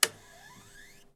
Apagado de una máquina de escribir electrónica
máquina de escribir
Sonidos: Oficina